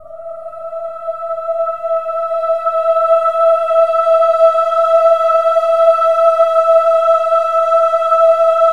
OH-AH  E5 -L.wav